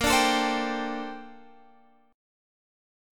A#M13 chord